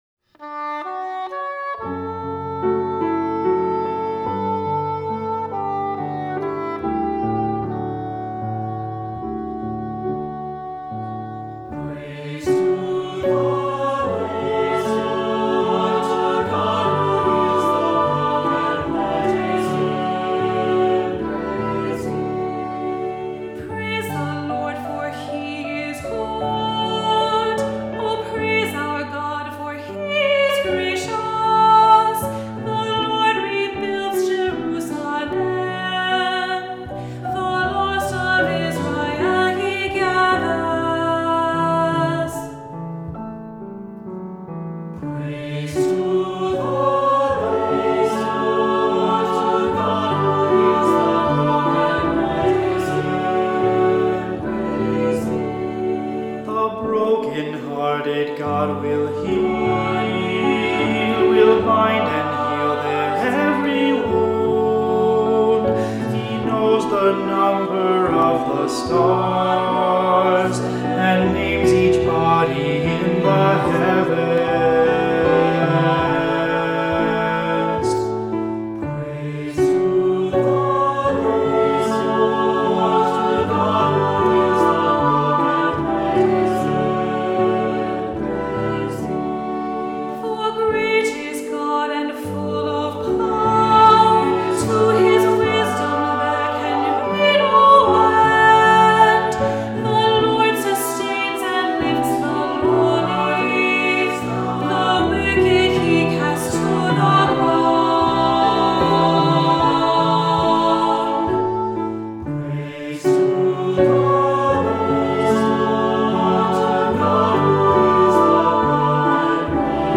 Voicing: Three-part choir; Cantor; Assembly